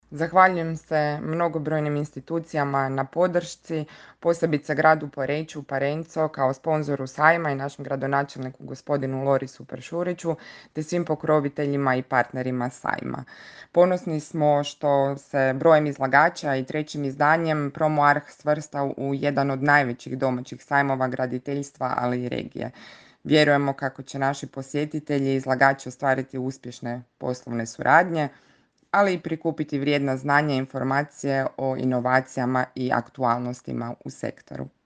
U Poreču je danas svečano otvoren PromoArh, jedini sajam graditeljstva, opremanja i uređenja u Istri, koji do 22. rujna djeluje kao jedinstvena platforma za povezivanje s ključnim igračima građevinske industrije, ali i nepresušan izvor inspiracije za projektante, inženjere građevine, vlasnike kuća za odmor ili one koji će tek početi s gradnjom.